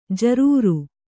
♪ jarūru